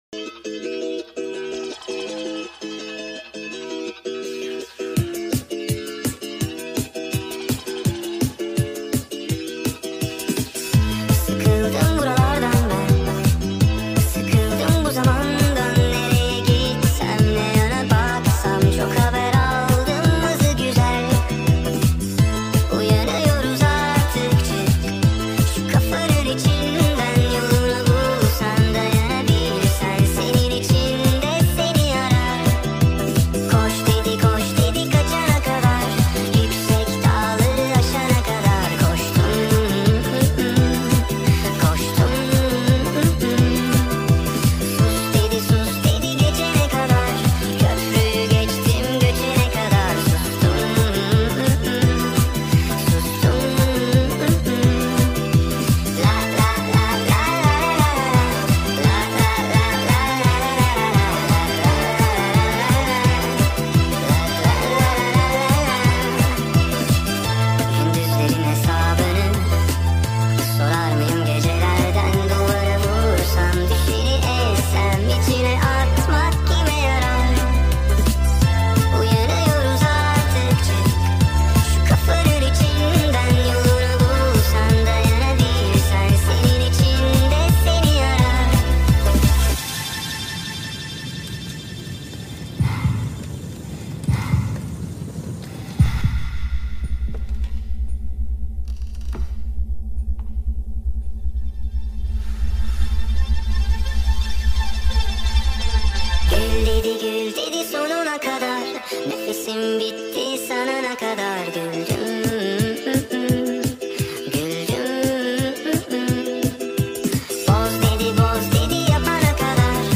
اهنگ ترکیه ای